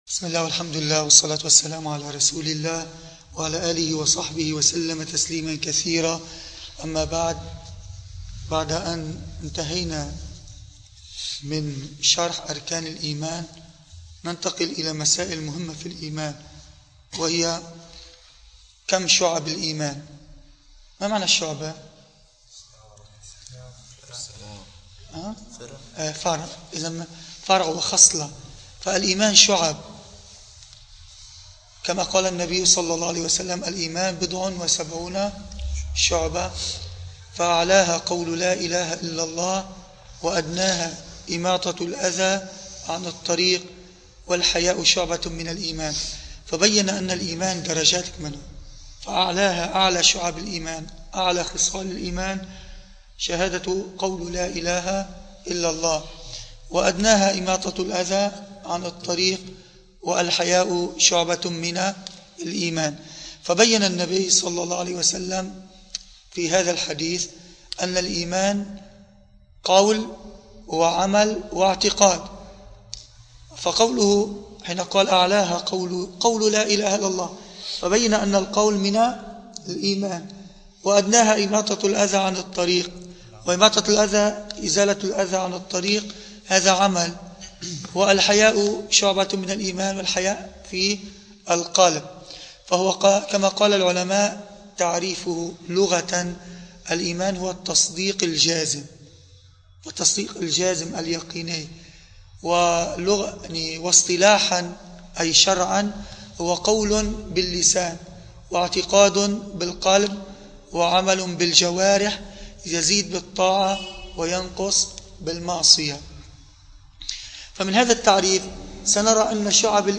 دروس عقيدة
المكان: مسجد القلمون الغربي